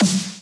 admin-fishpot/b_snare1_v127l8-3o5c.ogg